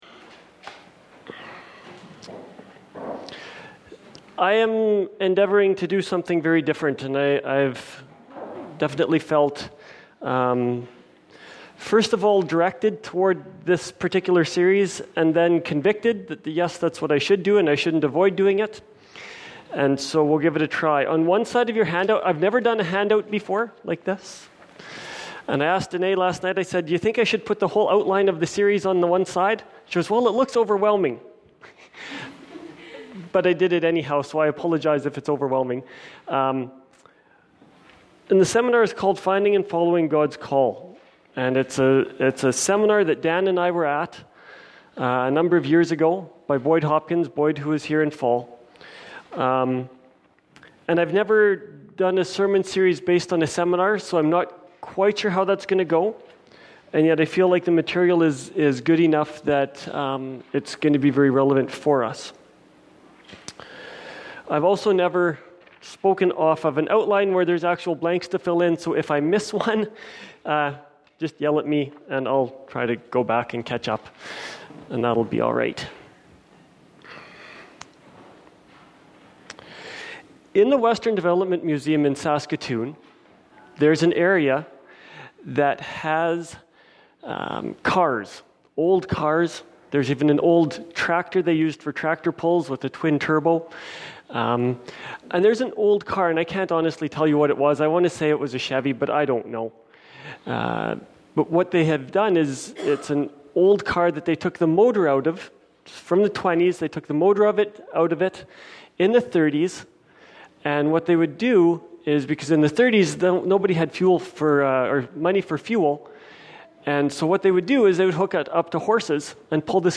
Finding-and-Following-Gods-Call-Serm.mp3